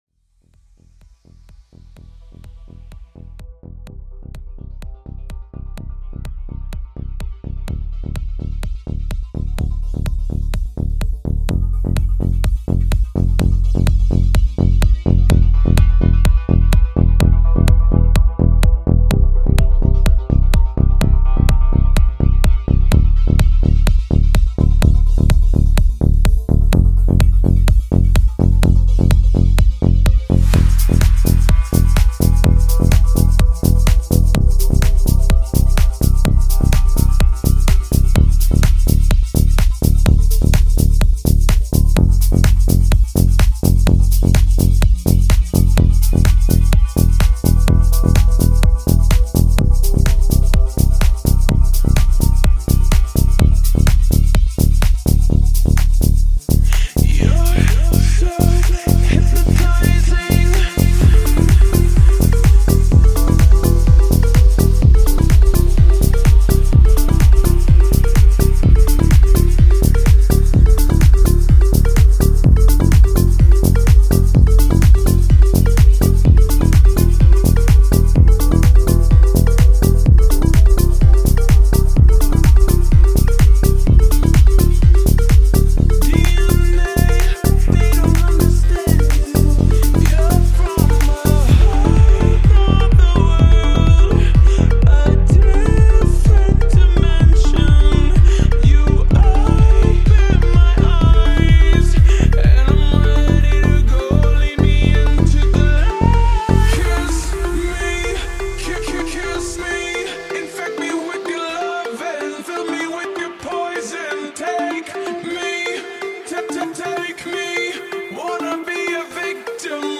High quality Sri Lankan remix MP3 (6.3).